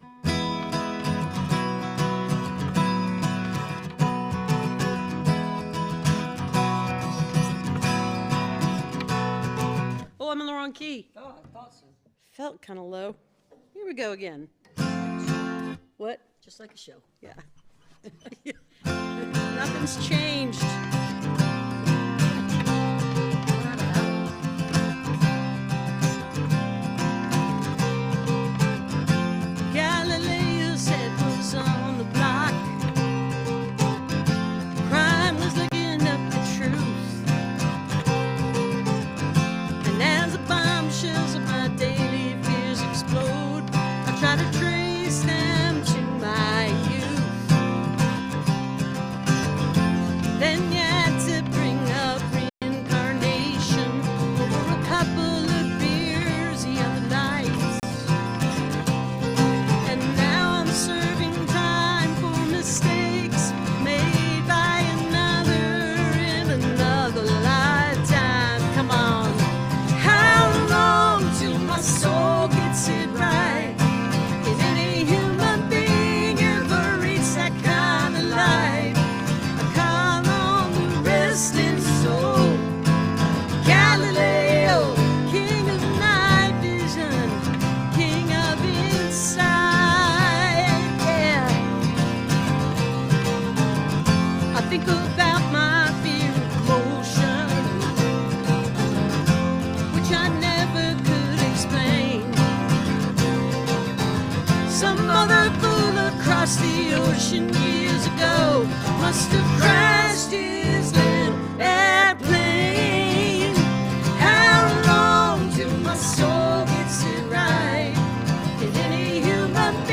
(captured from the youtube livestream)